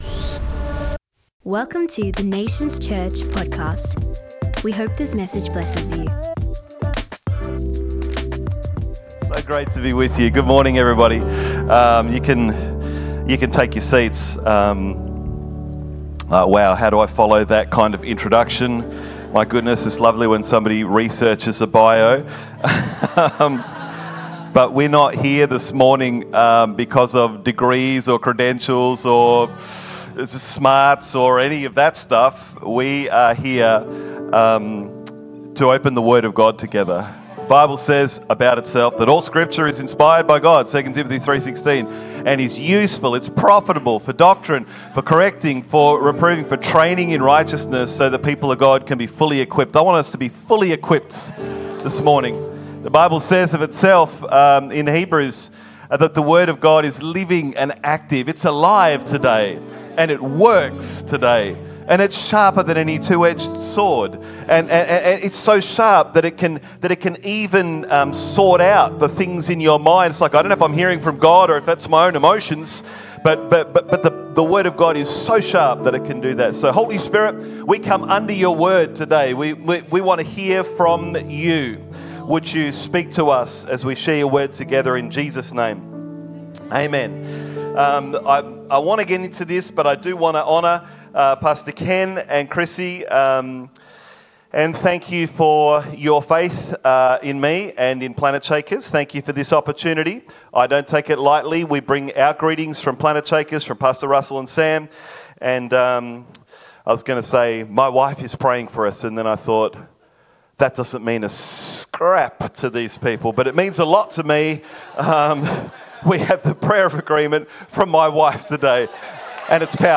Guest Ministry